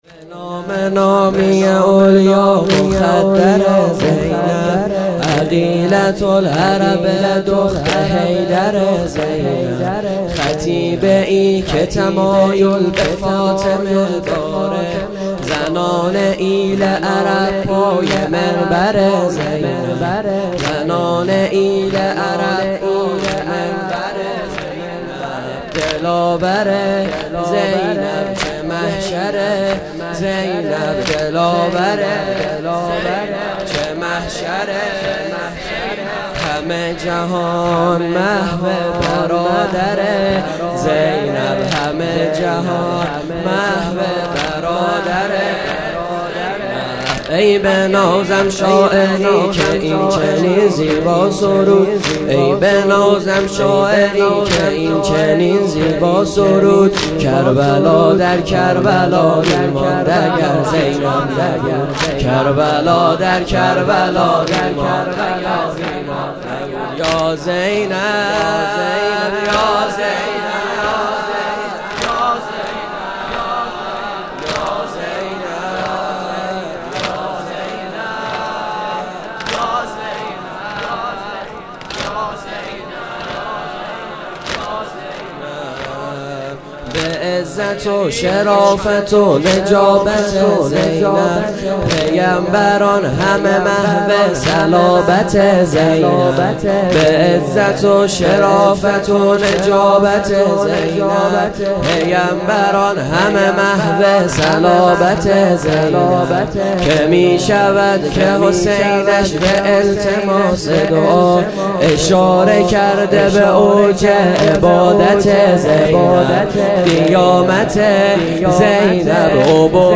شب چهارم - واحد | به نام نامی الیا مخدره زینب
5 شب عزاداری دهه سوم محرم 1441